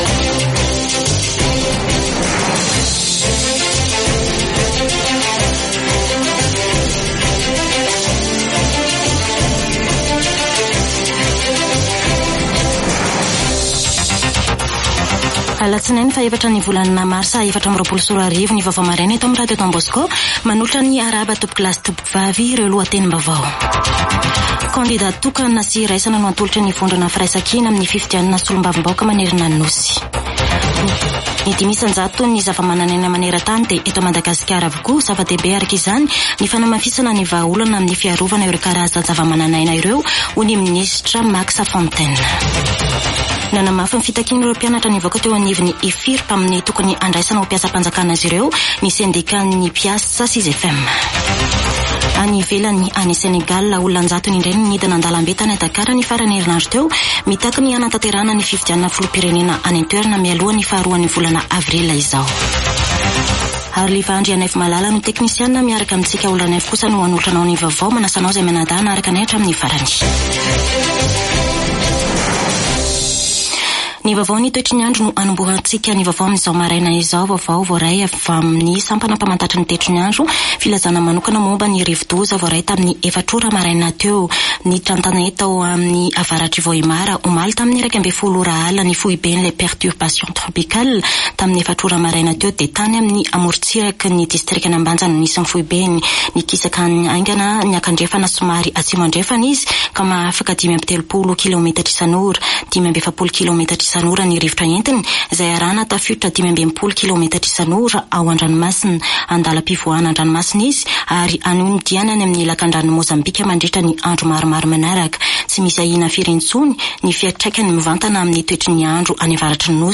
[Vaovao maraina] Alatsinainy 4 marsa 2024